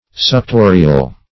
Suctorial \Suc*to"ri*al\, a. [L. sugere, suctum, to suck.]